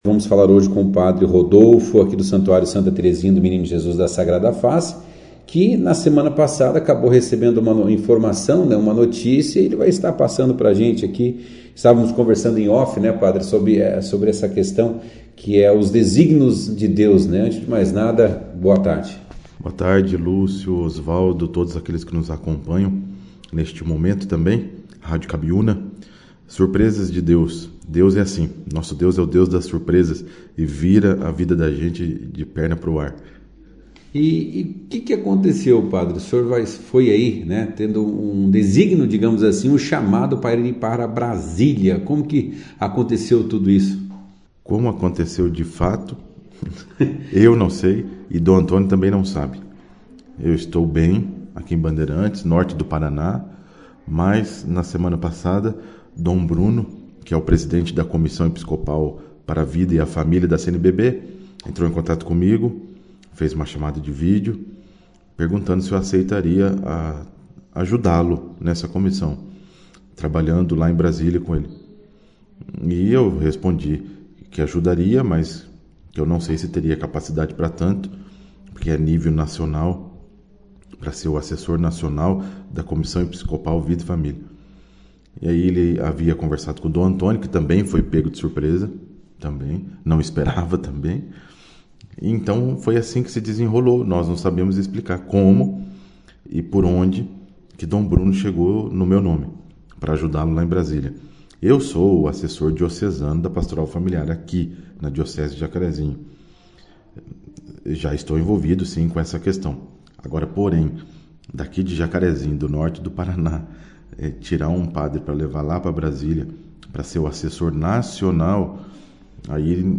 concedeu uma entrevista à 2ª edição do jornal Operação Cidade